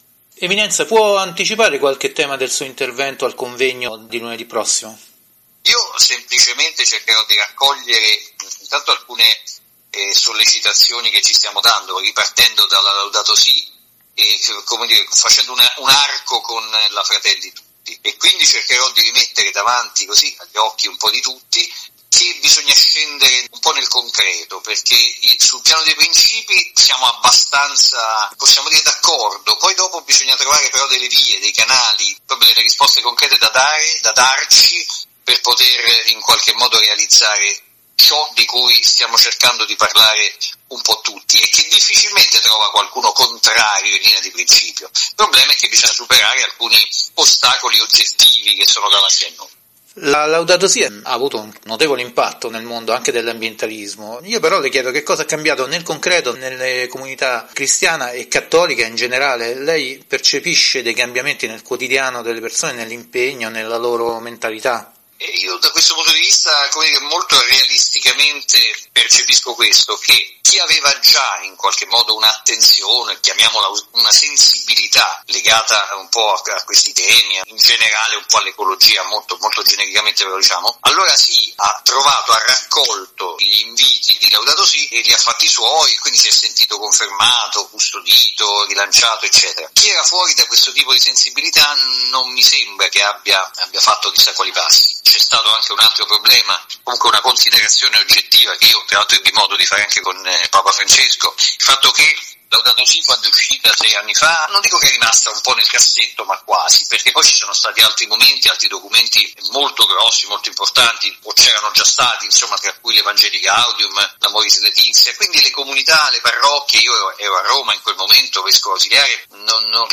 Card. Lojudice Arcivescovo di Siena
Di seguito la versione integrale dell’intervista, trasmessa oggi nella rubrica Ecosistema di Earth Day Italia, nel programma “Il Mondo alla Radio” di Radio Vaticana Italia.